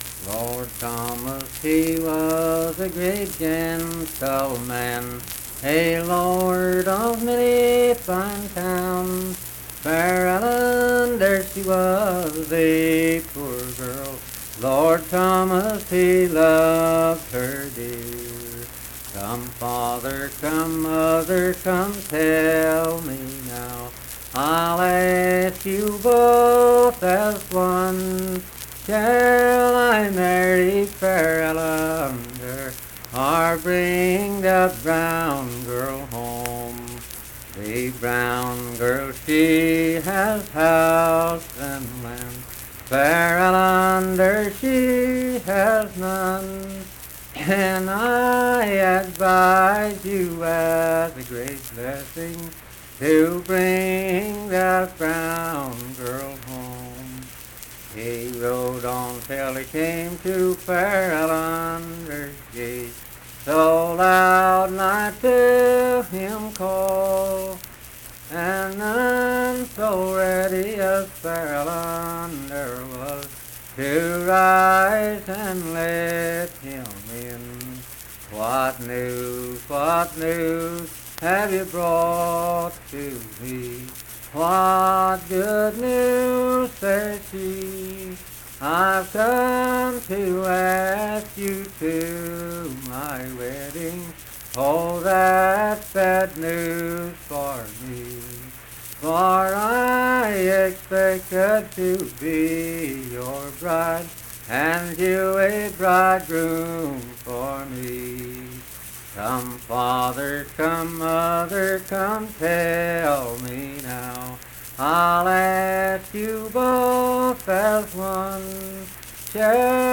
Unaccompanied vocal music performance
Performed in Kliny, Pendleton County, WV.
Voice (sung)